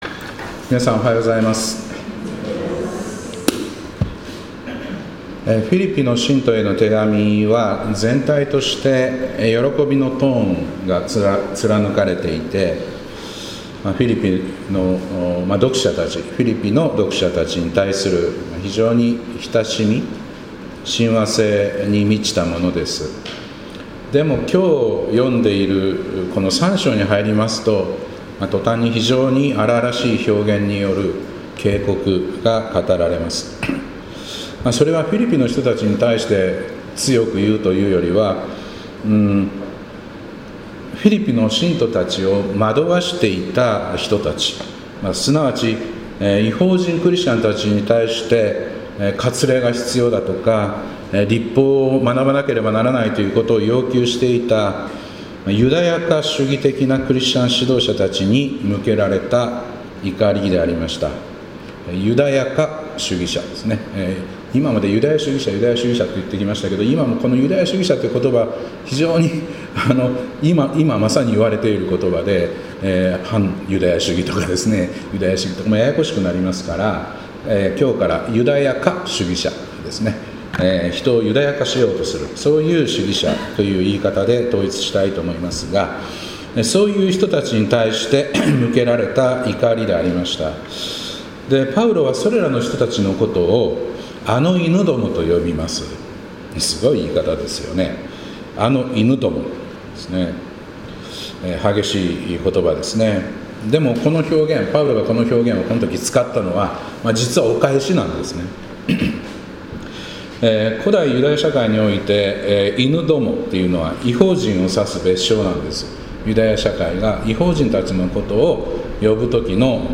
2025年6月15日礼拝「あの頃のぼくは」